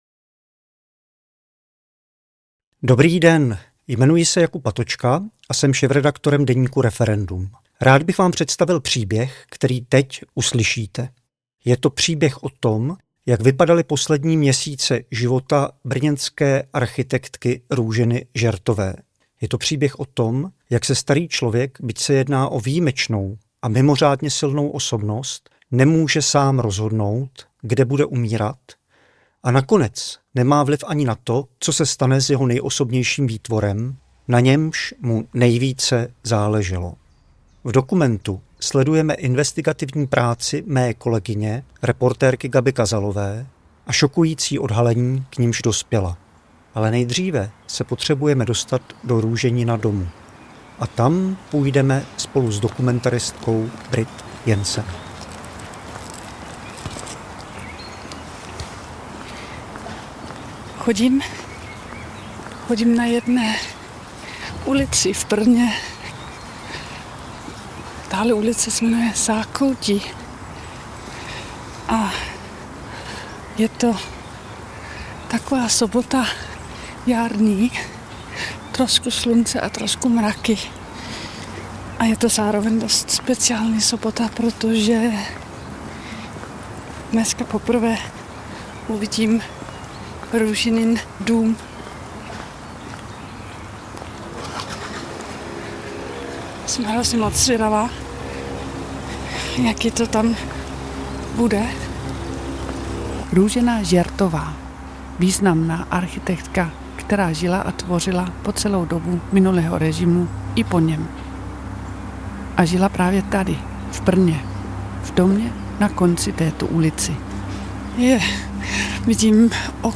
Dokumentární podcast vypráví temný příběh domu v ulici Zákoutí.